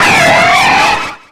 Cri de Pingoléon dans Pokémon X et Y.